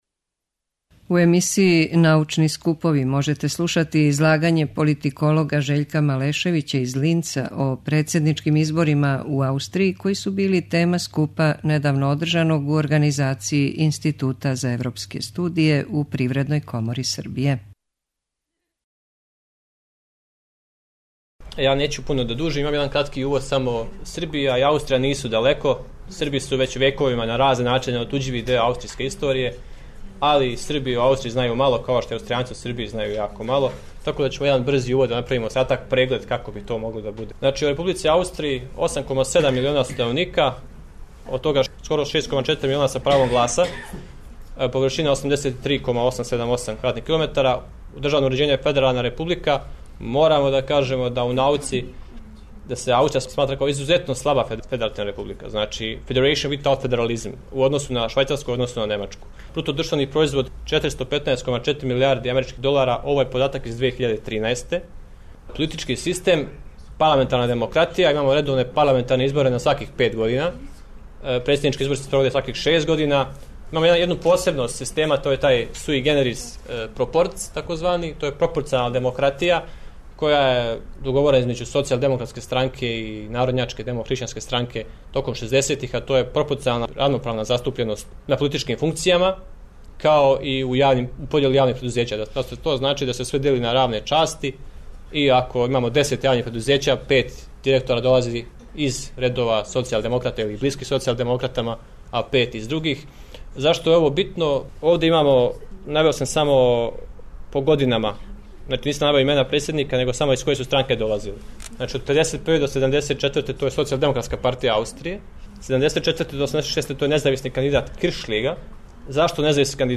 Научни скупови
преузми : 6.71 MB Трибине и Научни скупови Autor: Редакција Преносимо излагања са научних конференција и трибина.